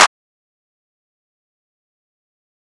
HYDRA-CLAP (Sizzle).wav